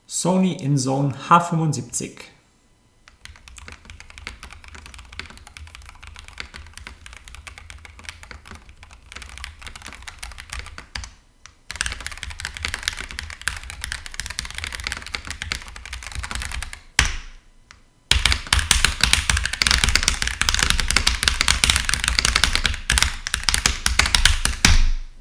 Akustik: mit Grundruhe
Mit sanfter Kraft betätigt ergibt sich ein leiser, satter Klangteppich mit gedämpften Anschlägen, nur leicht hörbarem „Klack“.
Das Tippen lässt sich hören, verschwindet aber aus der Wahrnehmung, denn Sony eliminiert helle Tonlagen und Hall extrem gut.
Unaufdringlich, dezent, klar – damit gefällt die KBD-H75 richtig gut.